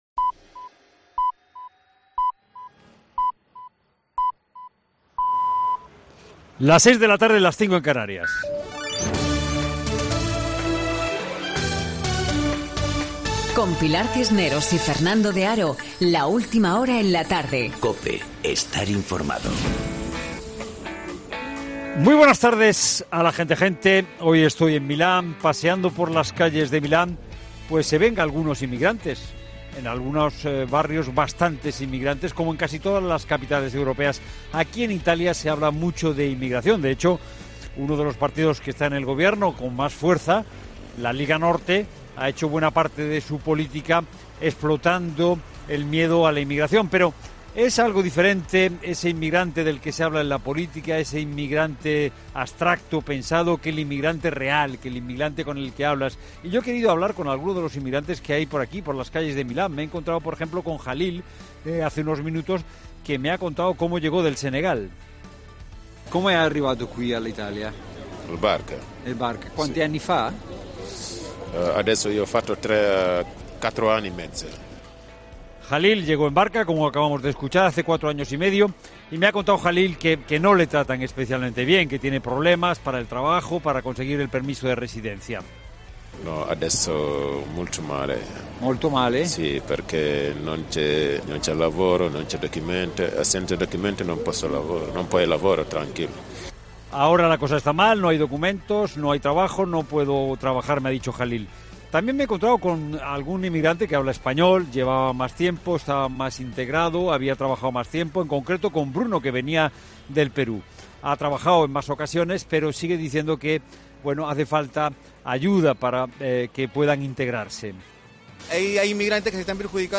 Monólogo
en las calles de Milán con algunos inmigrantes llegados a Italia